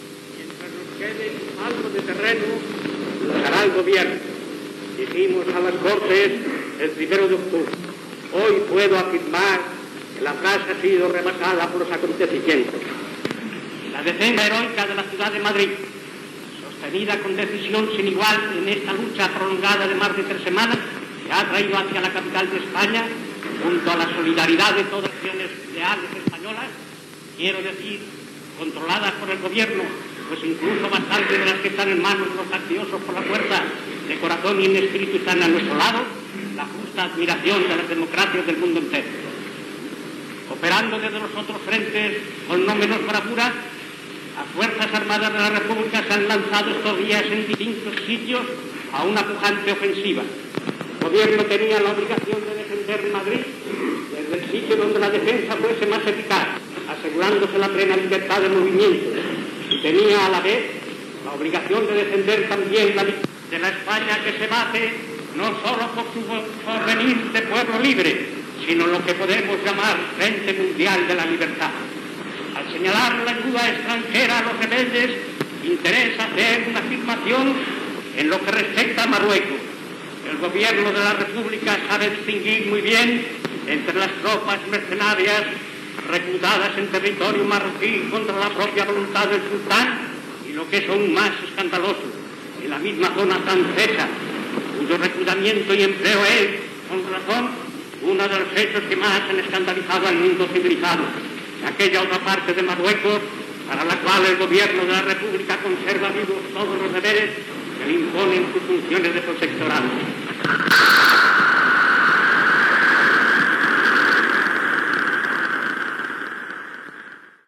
Paraules de Francisco Largo Caballero, president del Consejo de Ministros de la República Española, a les Cortes de la República reunides a València.
Informatiu